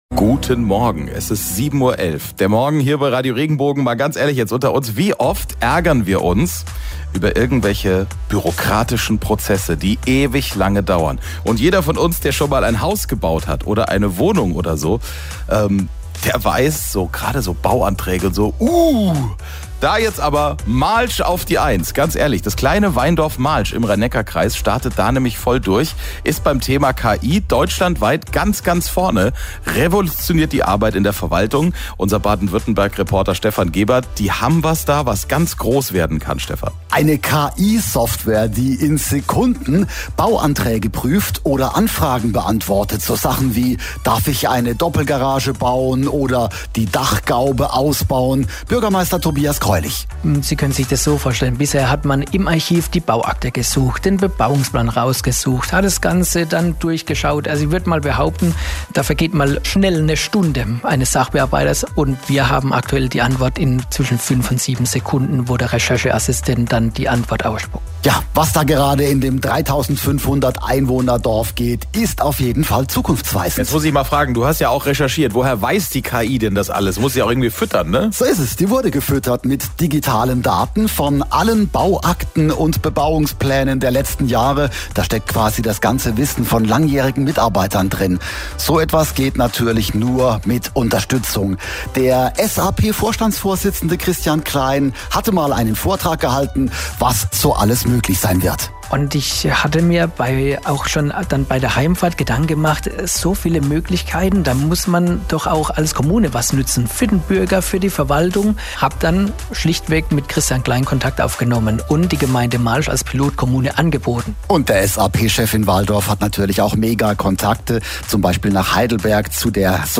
Der Radiosender Radioregenbogen hat in seinem aktuellen Beitrag zur Digitalisierung in der öffentlichen Verwaltung unser Pilotprojekt als beispielgebend und richtungsweisend hervorgehoben.
Download Audiodatei Mittschnitt Radio Regenbogen, Digitalisierung Gemeinde Malsch